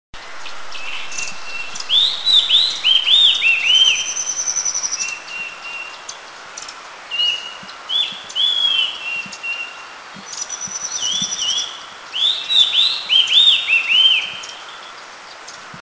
Rufous-chinned Laughingthrush
song -beauty, species only on B2A
G. rufogularis
Rufous-chinnedLTsong.mp3